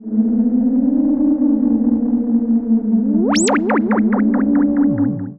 Theremin_Swoop_11.wav